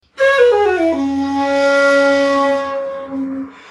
Shakuhachi 55